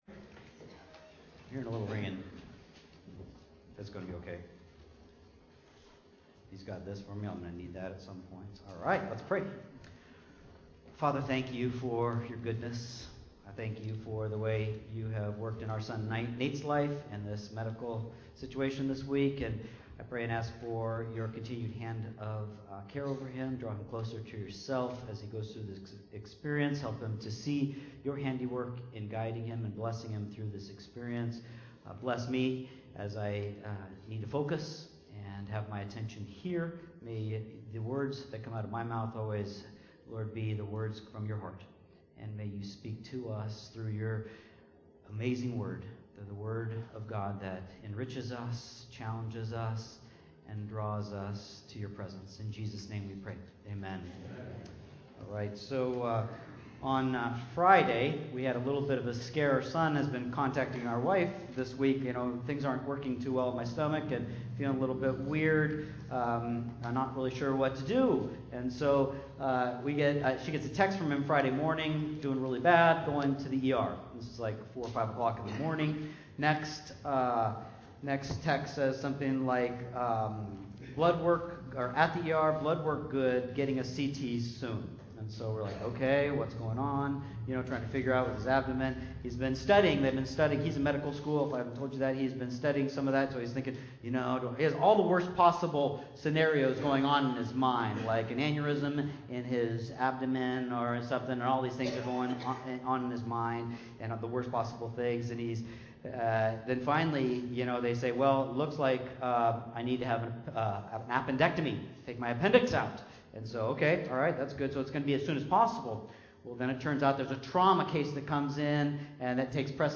2-2-20-1ST-SERVICE_session-Converted.mp3